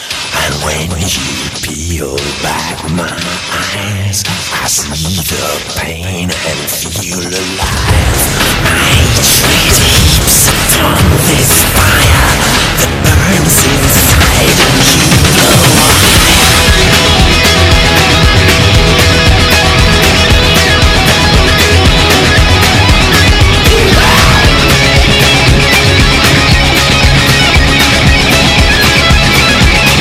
Nada dering top funny music
Alternative